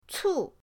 cu4.mp3